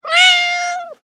جلوه های صوتی
دانلود صدای میو گربه از ساعد نیوز با لینک مستقیم و کیفیت بالا